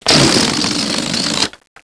ToobzDeflated.wav